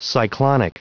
Prononciation du mot cyclonic en anglais (fichier audio)
Prononciation du mot : cyclonic